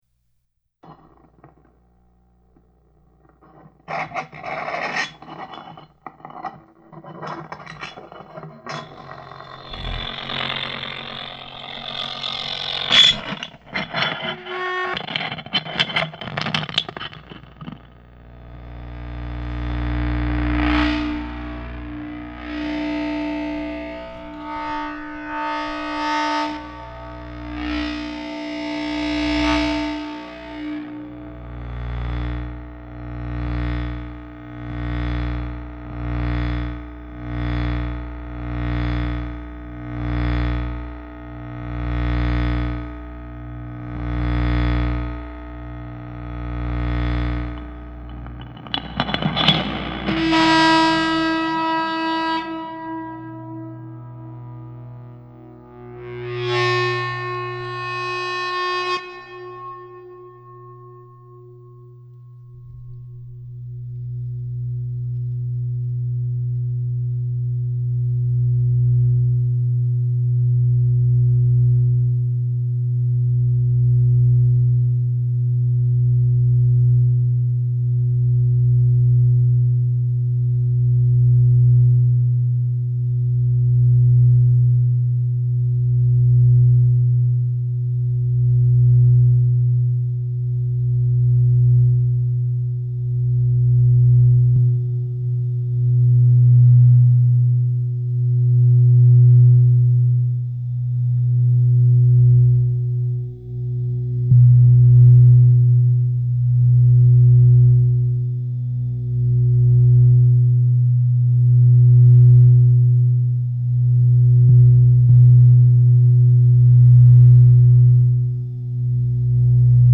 Sound art
speaker feedback instruments + voice